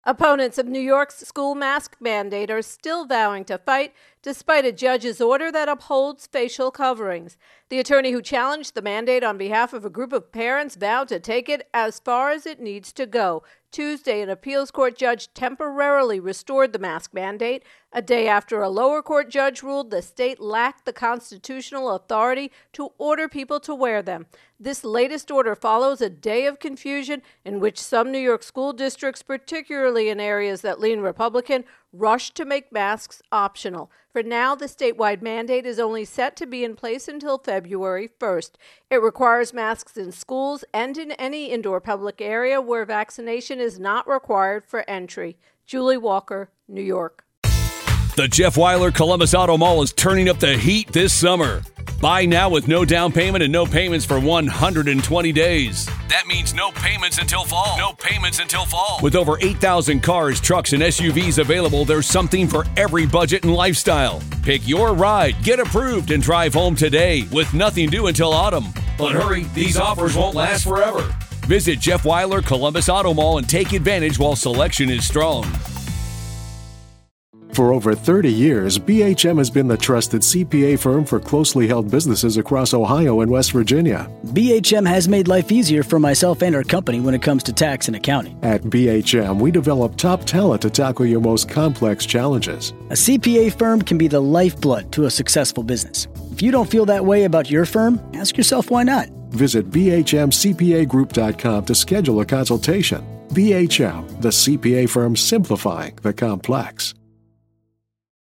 Virus Outbreak NY Mask Mandate intro and voicer